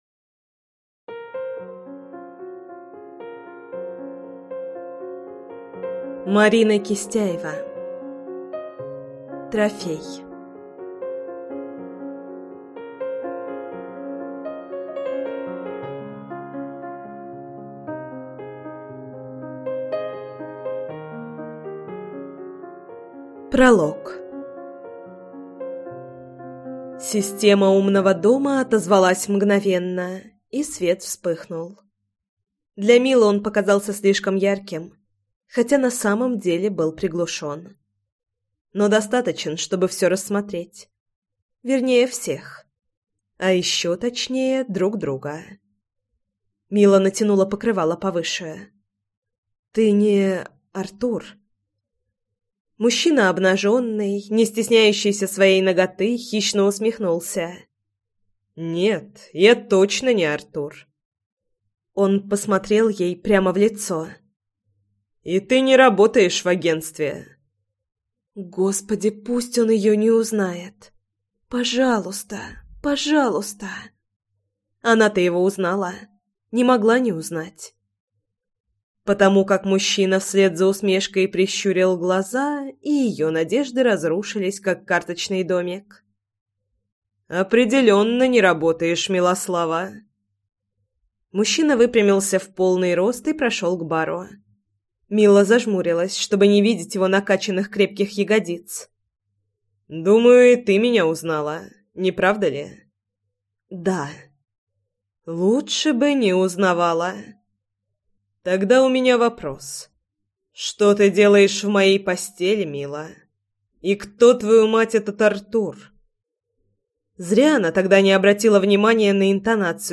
Аудиокнига Трофей | Библиотека аудиокниг